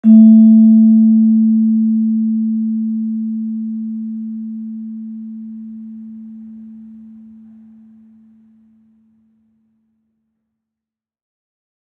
Gamelan Sound Bank
Gender-2-A4-f.wav